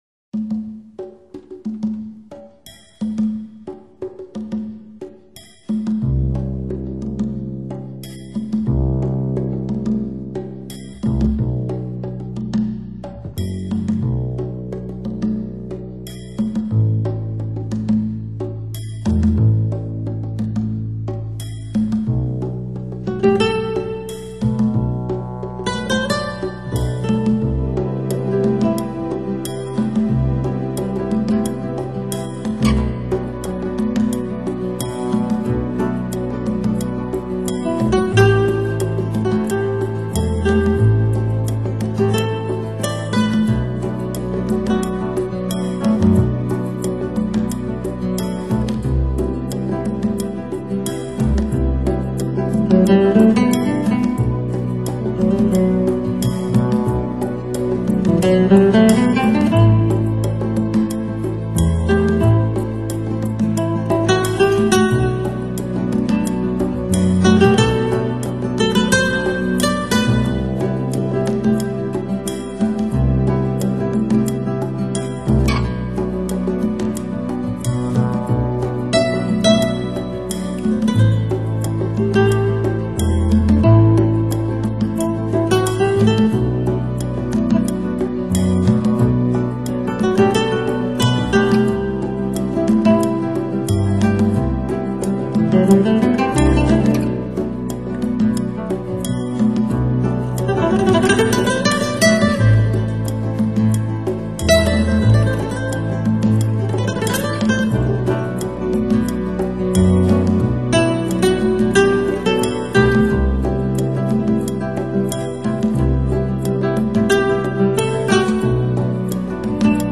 音乐类别：精神元素